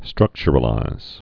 (strŭkchər-ə-līz)